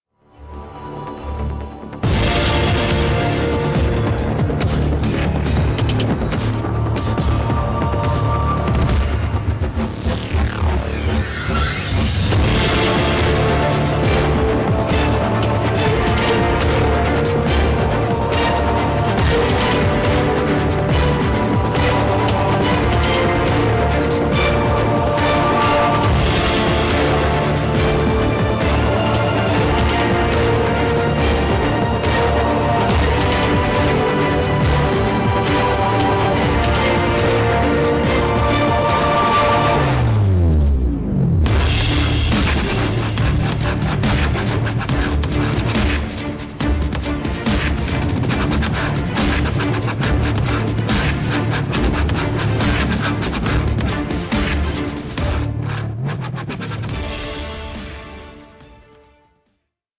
• Musica
Original track music